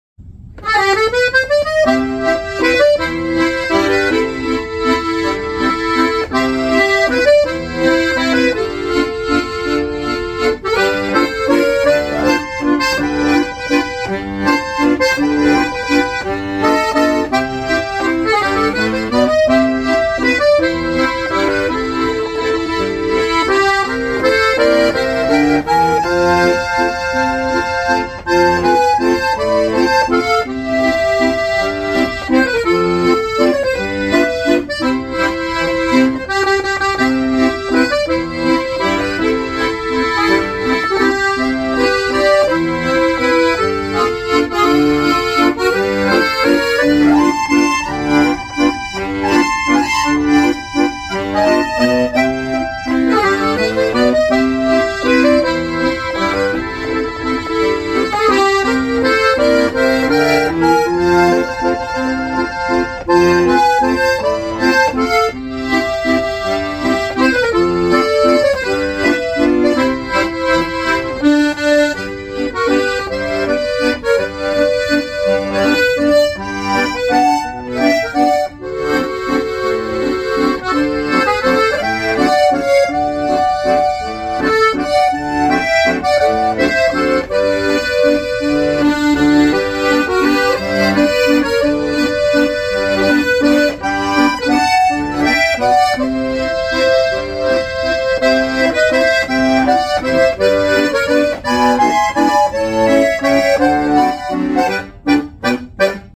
Accordion Man Waltz